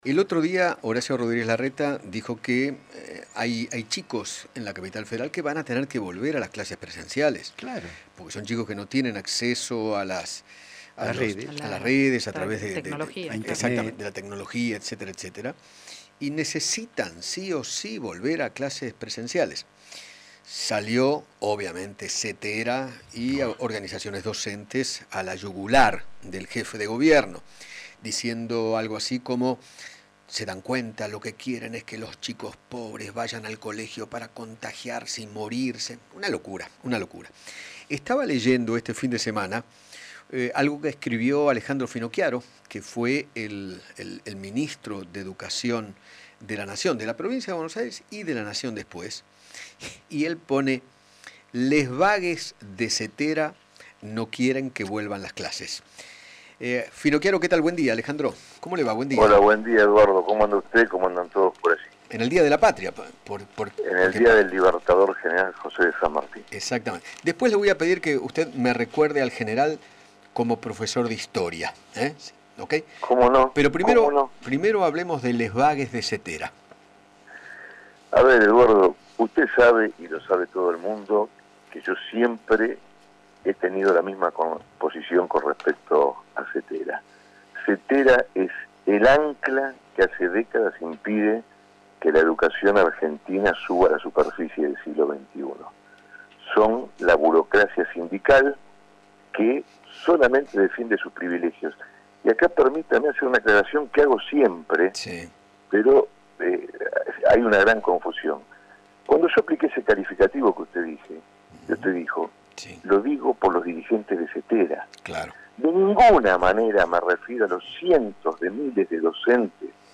Alejandro Finocchiaro, ex ministro de Educación, dialogó con Eduardo Feinmann sobre el rechazo de la Confederación de Trabajadores de la Educación de la República Argentina ante la propuesta de Horacio Rodríguez Larreta, quien planteó que aquellos chicos que no tienen acceso a la educación a distancia puedan volver a clases este año.